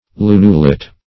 Search Result for " lunulet" : The Collaborative International Dictionary of English v.0.48: Lunulet \Lu"nu*let\, n. [Dim. of lunule.]